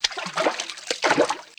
MISC Water, Splash 04.wav